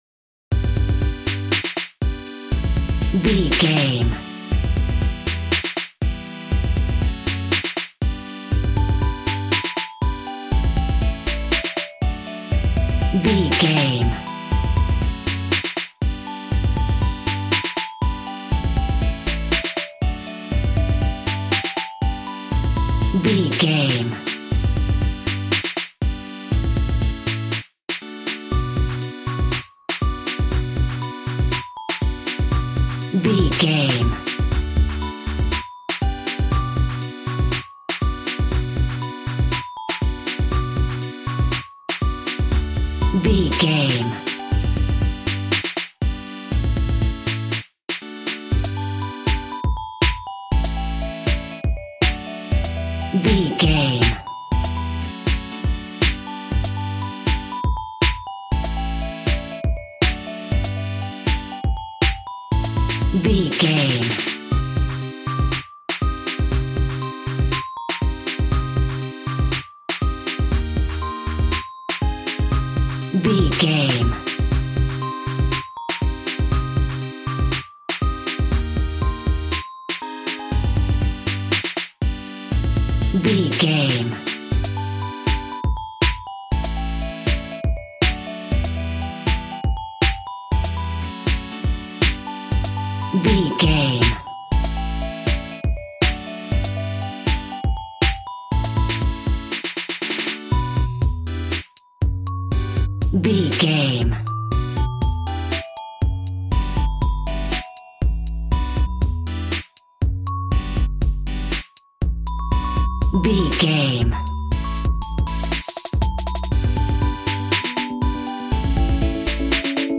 Aeolian/Minor
Fast
groovy
synthesiser
drums
cool
piano